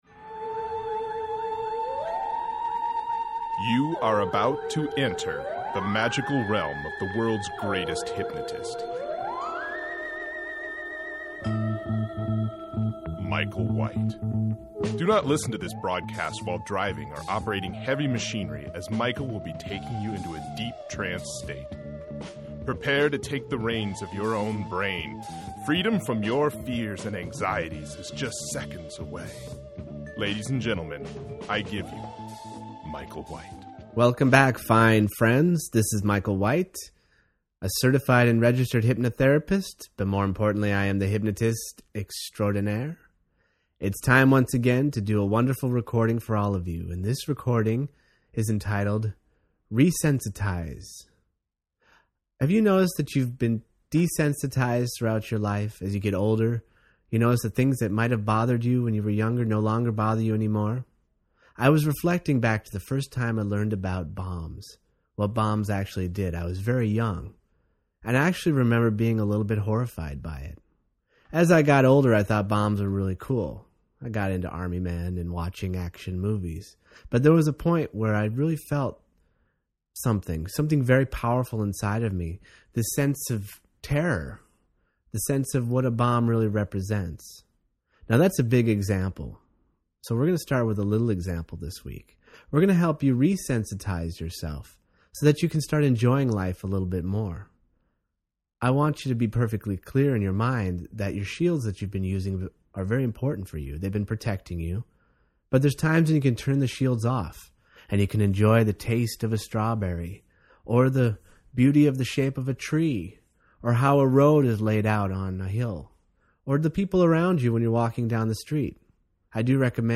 Tags: Hypnosis Free Hypnosis